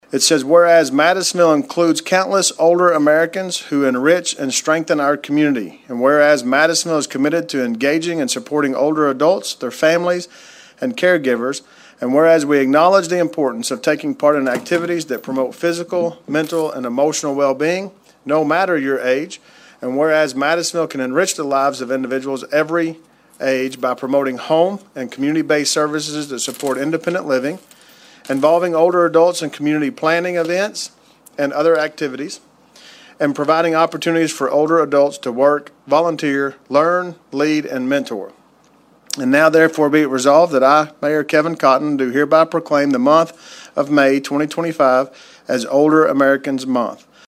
Mayor Cotton presented the proclamation at the City Council meeting Monday night.